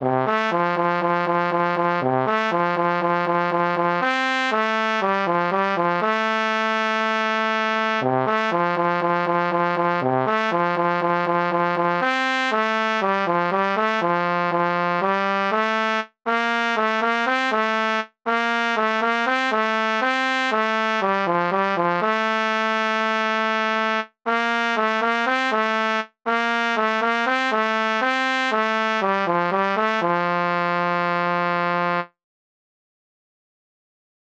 Jewish Folk Song
F major ♩= 120 bpm